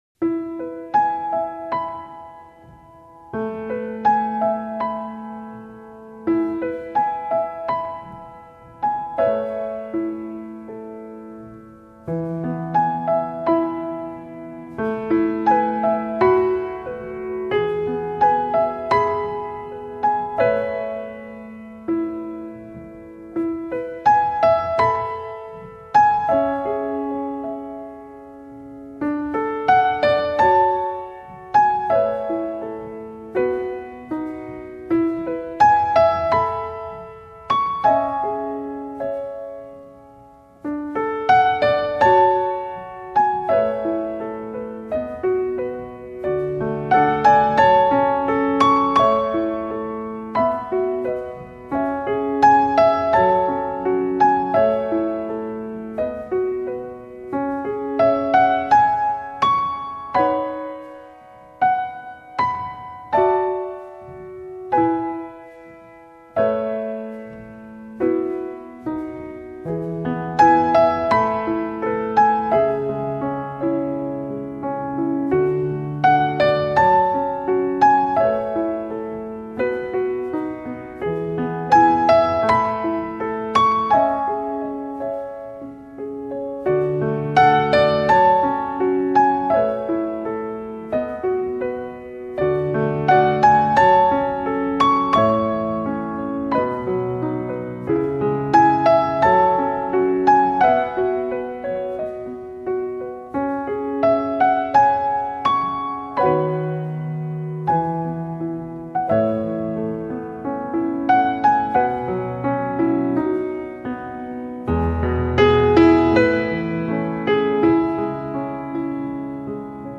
那种恬静纯然的新世纪纯音乐，
很喜欢这张精选，喜欢编者挑选曲目的精心安排，全然的舒服和放松，
一切就有这种音乐来托起，没有负担，没有压力。